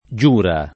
Giura [ J2 ra ] top. m.